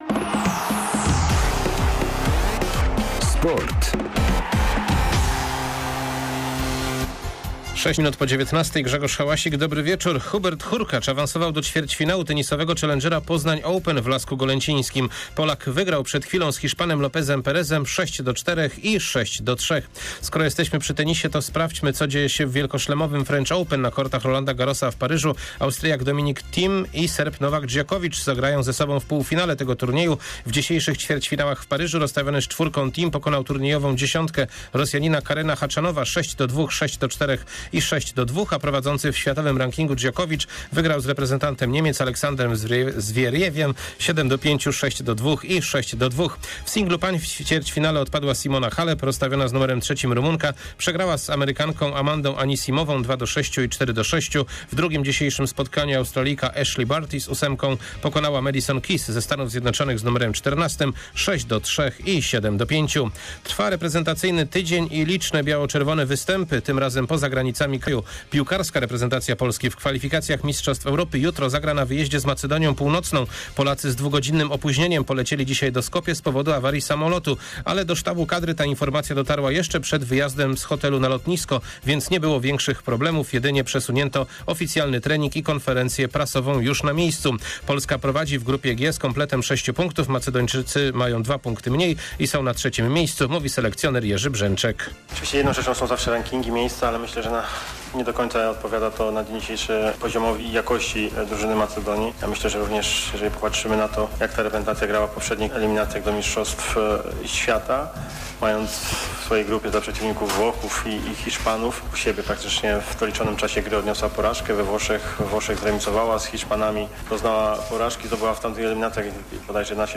06.06. serwis sportowy godz. 19:05